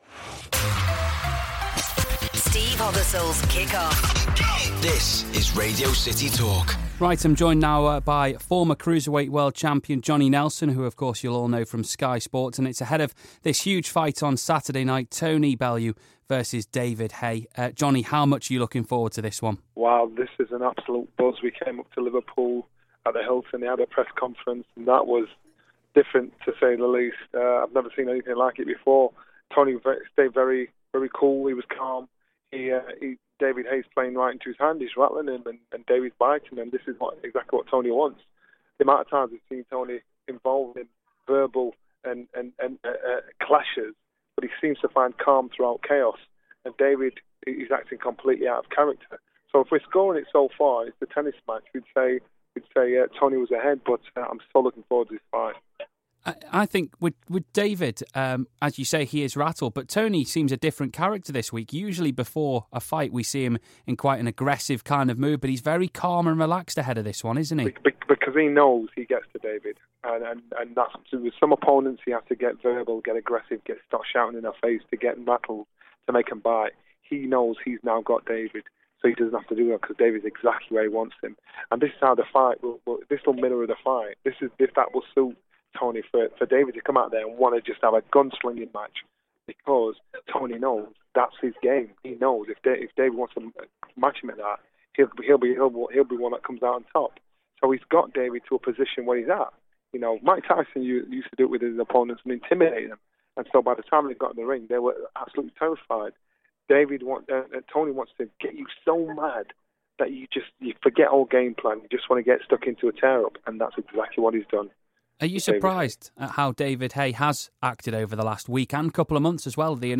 Former cruiserweight champ chats